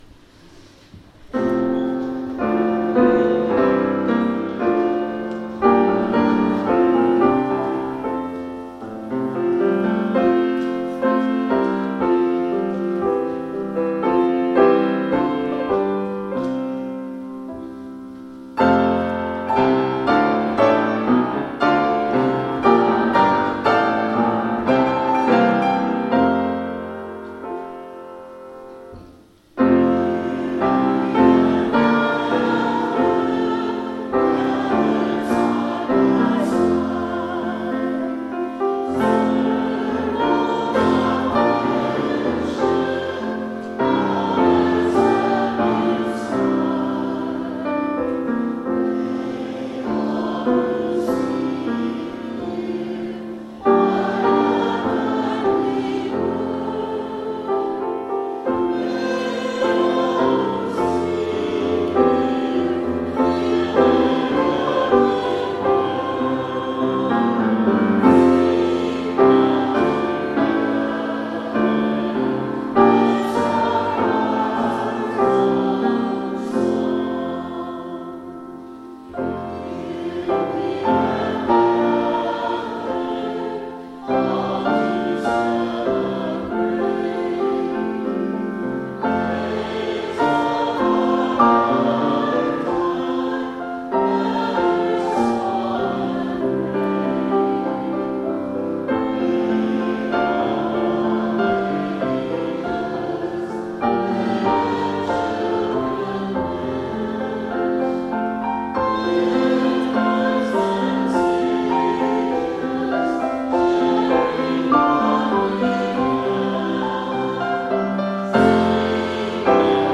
[Fuusm-l] Congregational Hymn
Music: Genevan Psalter, 1543